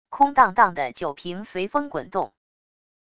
空荡荡的酒瓶随风滚动wav音频